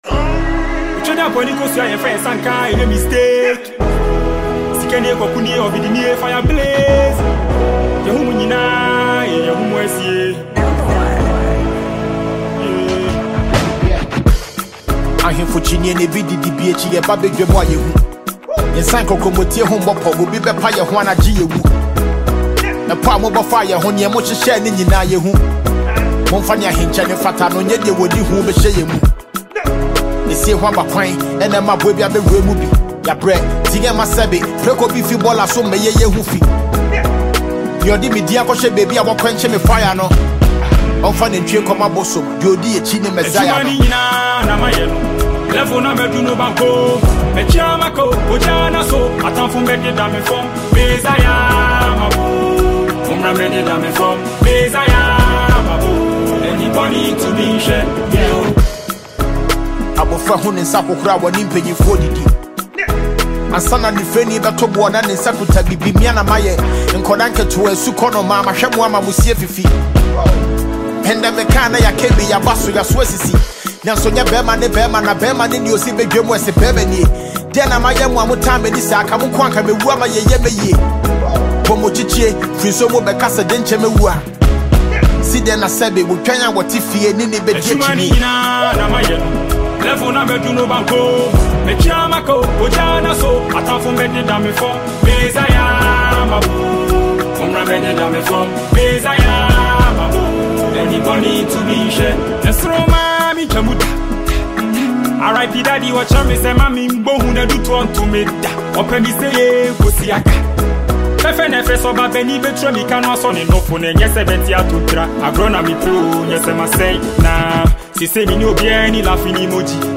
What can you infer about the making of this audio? studio track